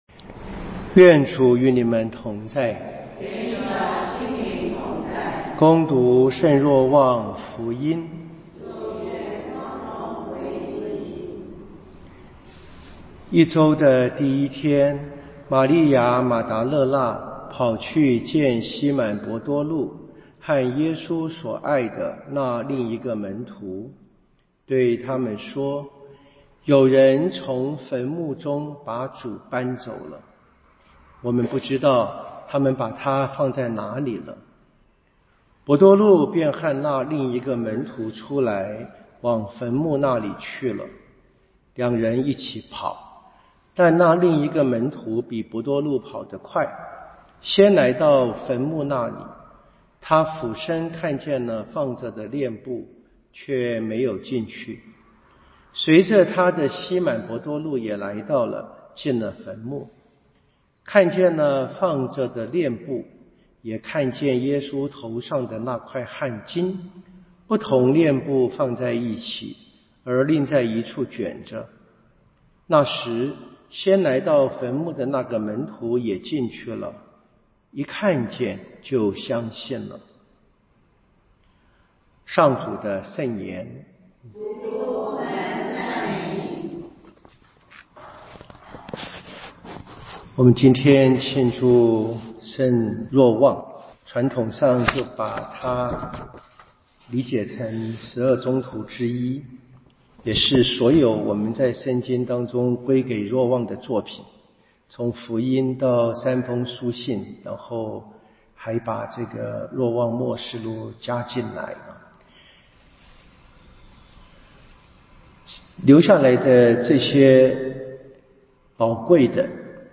彌撒講道與聖經課程